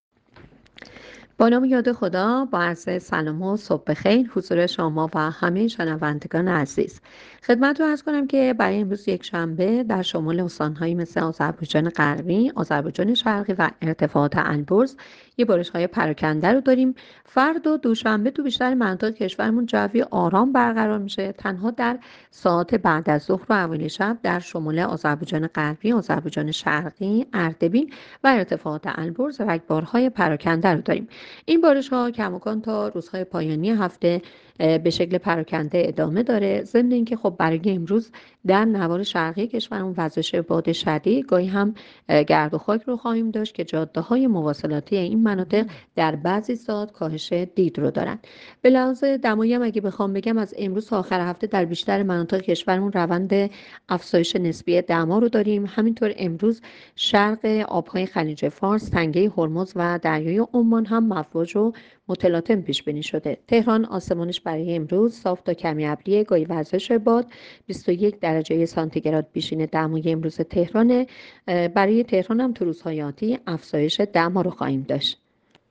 گزارش رادیو اینترنتی پایگاه‌ خبری از آخرین وضعیت آب‌وهوای ۳۱ فروردین؛